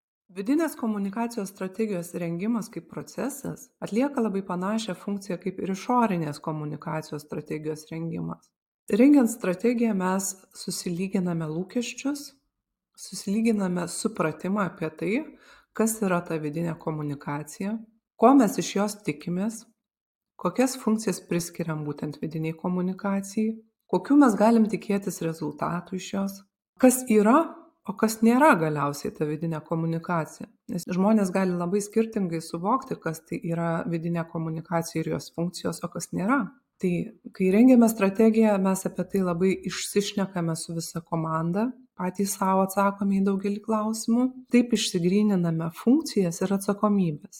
Komunikacijos strategė